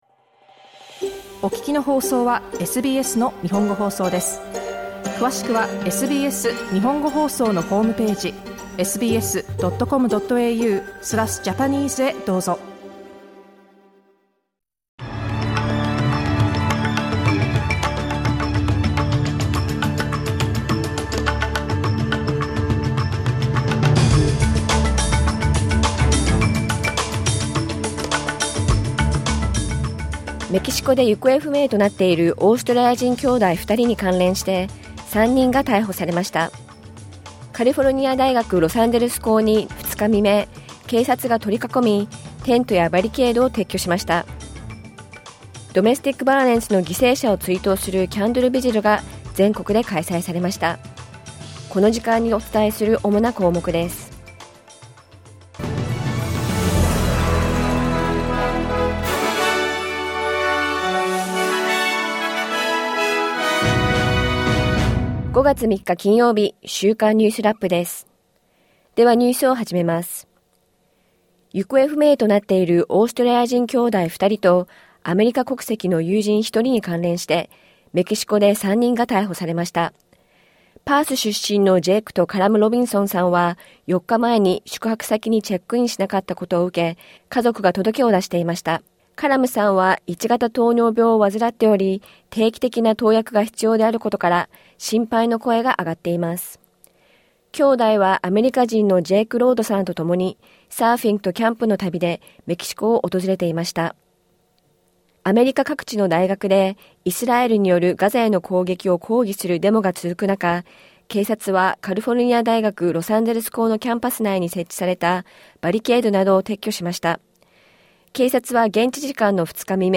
SBS日本語放送週間ニュースラップ 5月3日金曜日